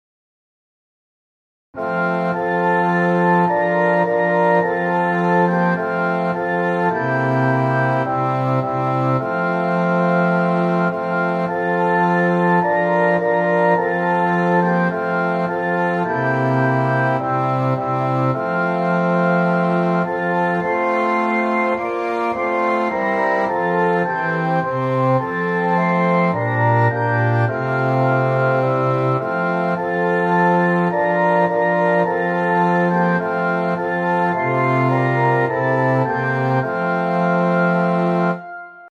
Hymns of praise
Orchestral Version (.mp3)